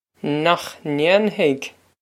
Pronunciation for how to say
Nokh nain-hig?
This is an approximate phonetic pronunciation of the phrase.
This comes straight from our Bitesize Irish online course of Bitesize lessons.